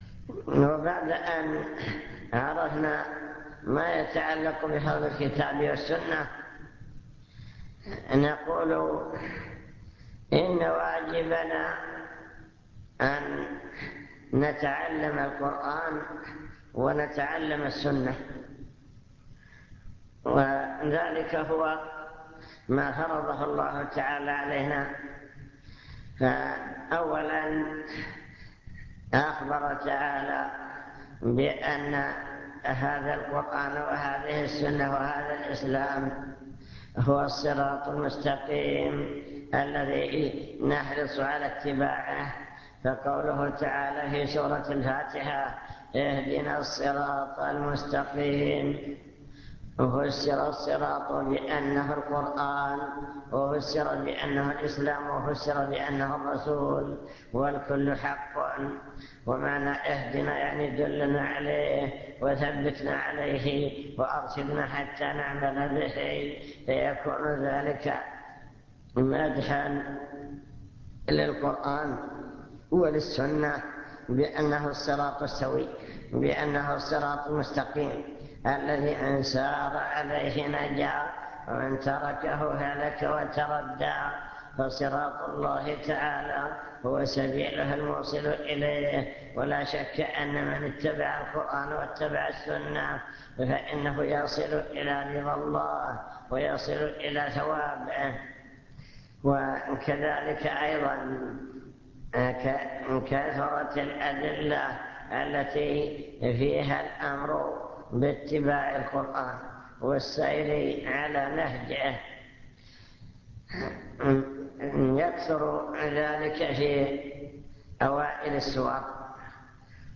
المكتبة الصوتية  تسجيلات - محاضرات ودروس  محاضرة عن القرآن والسنة